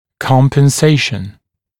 [ˌkɔmpən’seɪʃn][ˌкомпэн’сэйшн]компенсация